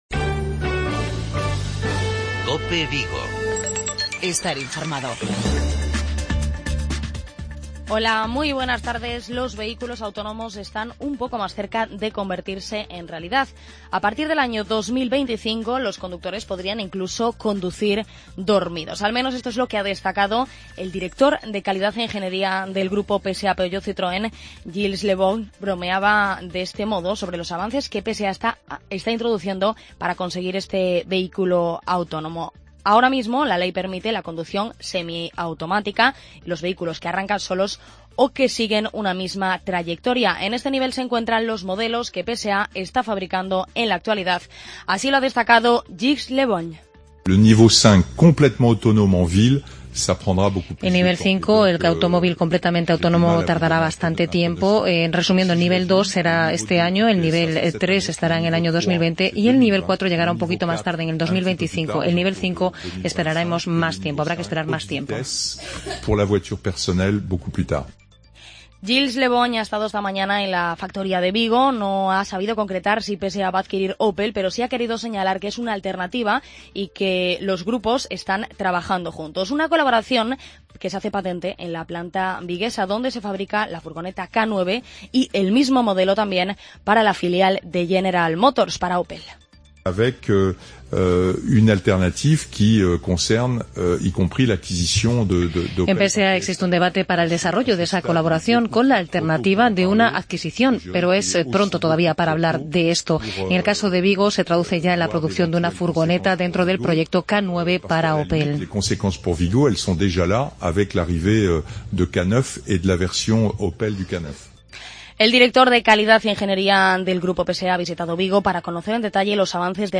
Informativos Vigo
AUDIO: Informativos Vigo